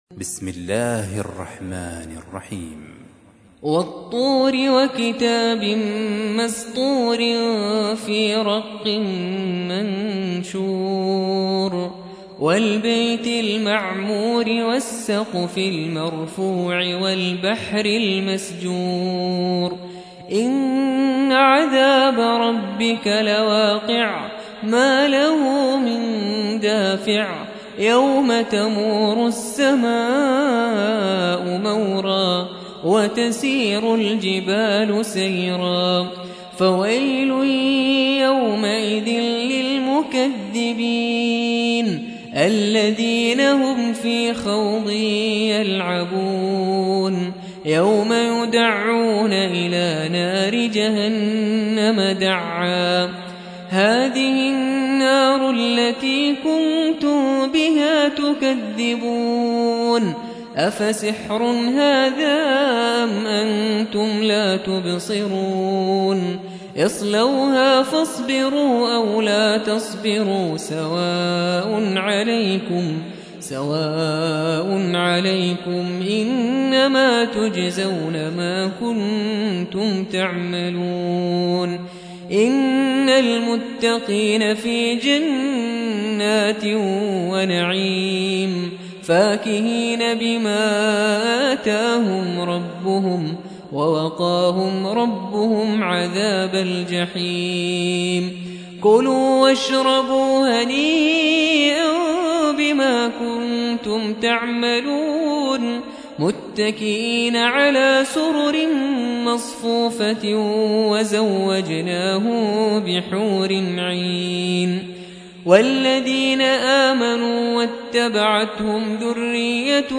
52. سورة الطور / القارئ